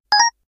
sound_menu_select.wav